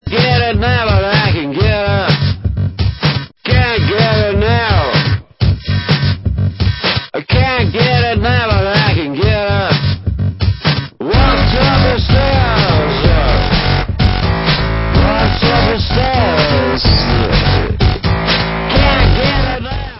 Alternativní hudba